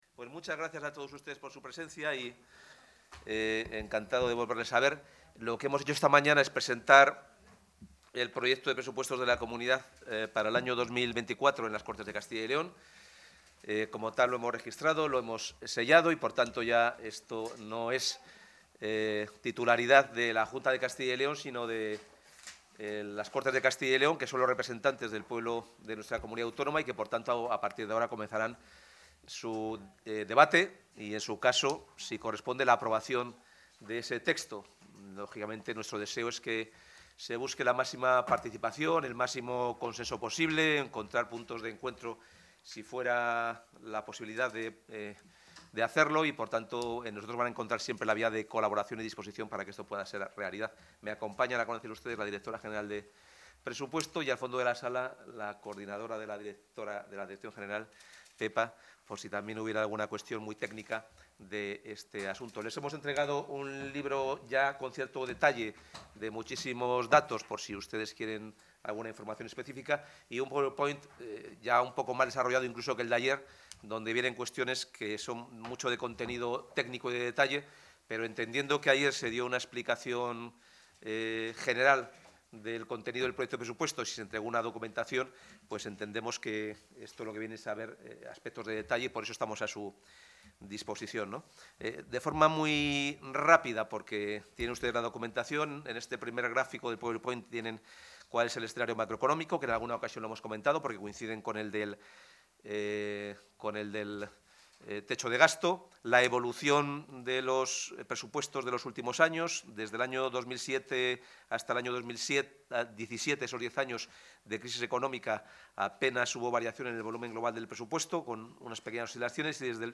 Intervención del consejero